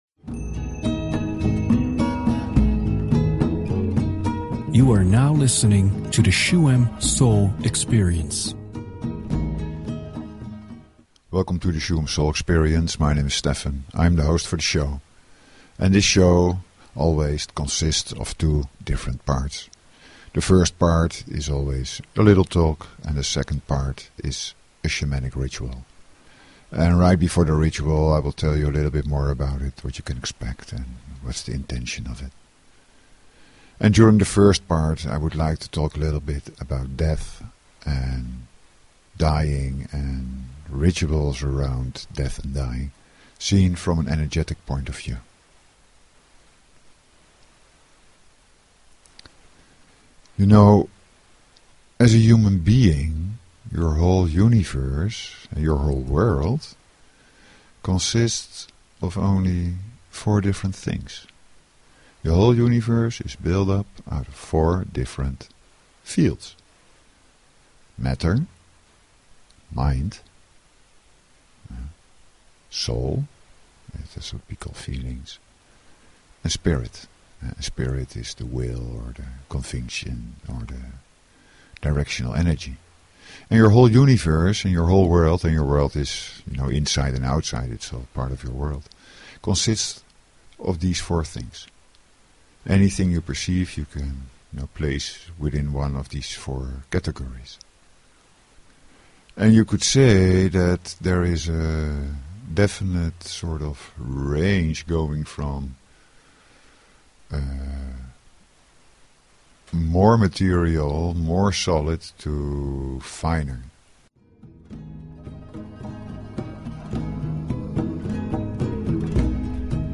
Talk Show Episode, Audio Podcast, Shuem_Soul_Experience and Courtesy of BBS Radio on , show guests , about , categorized as
To fully share in the experience, it is recommended to listen to the ritual through headphones, not to get disturbed, sit or lie down, relax and enjoy.